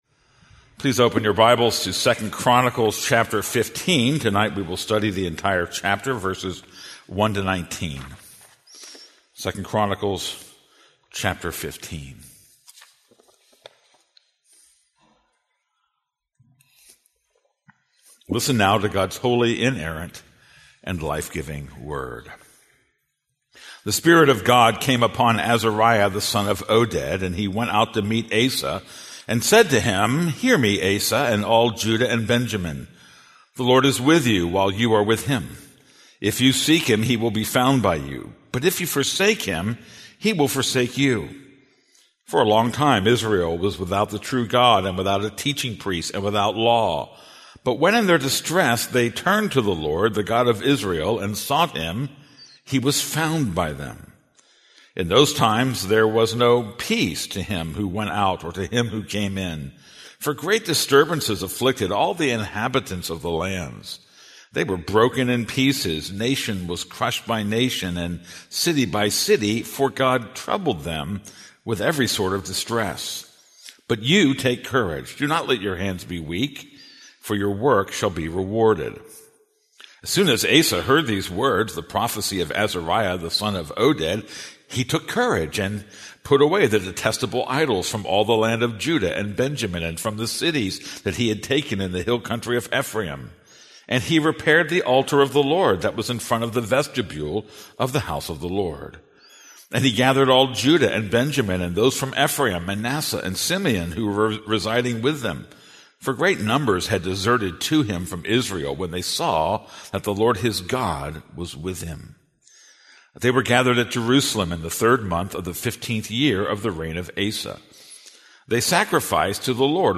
This is a sermon on 2 Chronicles 15:1-19.